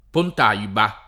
[ pont # iba ]